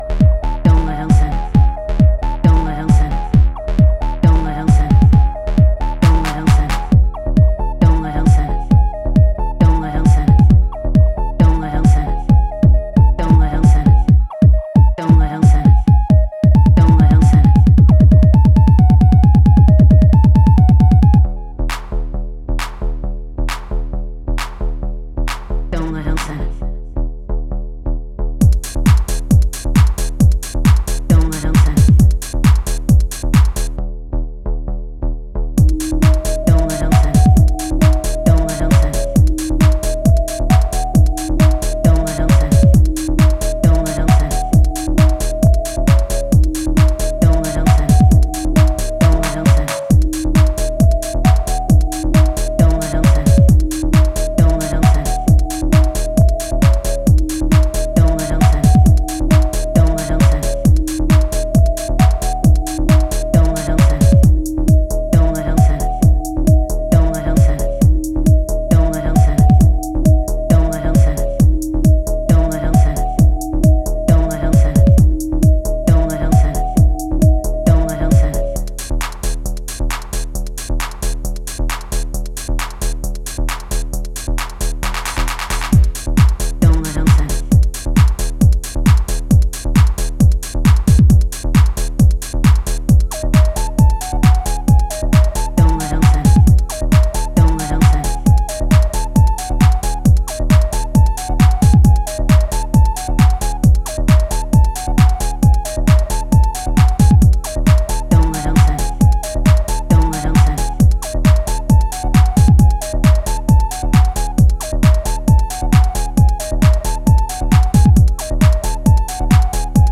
EBM感覚のダークネスが息づくテック・ハウスを展開